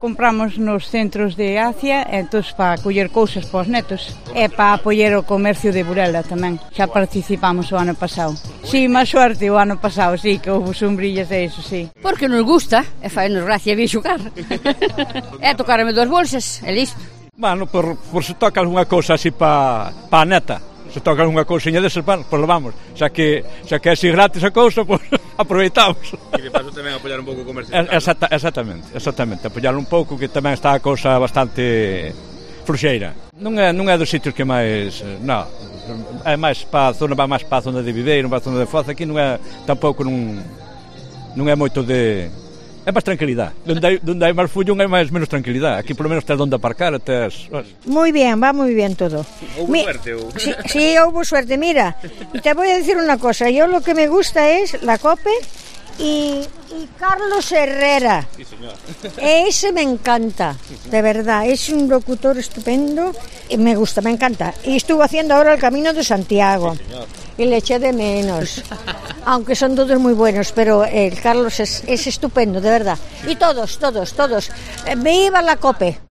Os clientes do CCA Burela falan sobre a 'Ruleta da Sorte' da Federación de Comercio